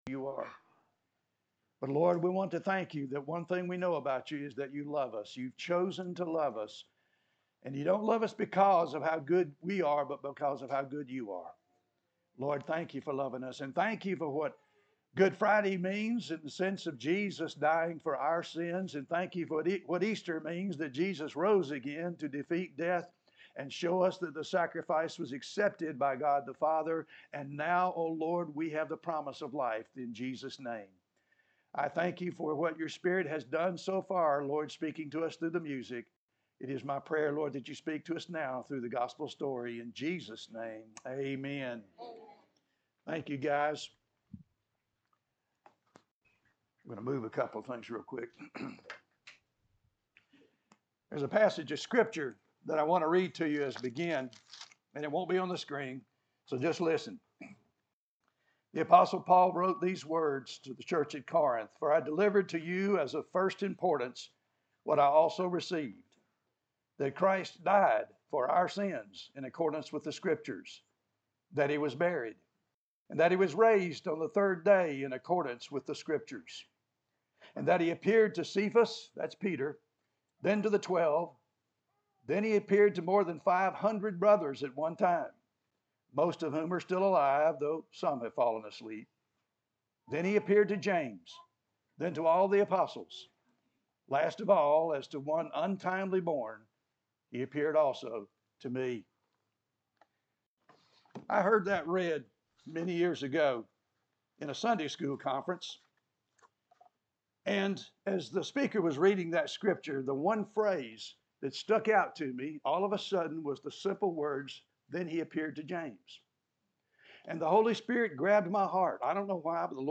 Story Sermons